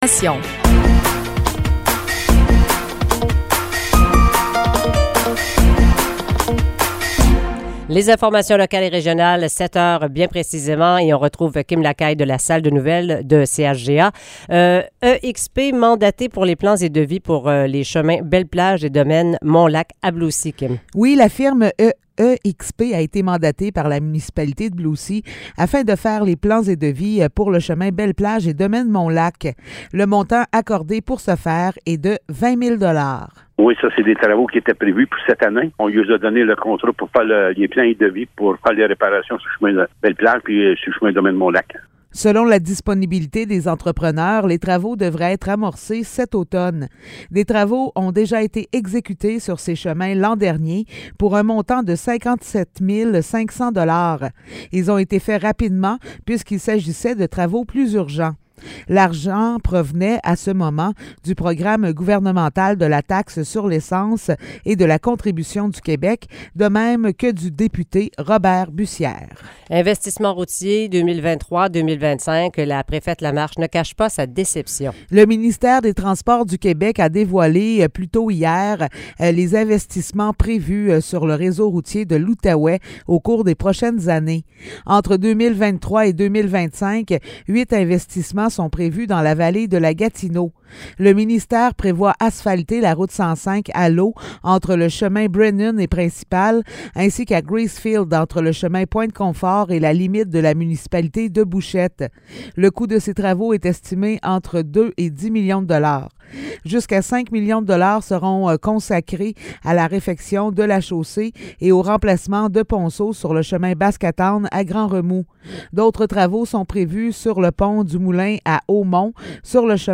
Nouvelles locales - 14 avril 2023 - 7 h